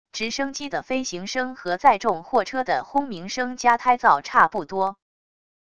直升机的飞行声和载重货车的轰鸣声加胎噪差不多wav音频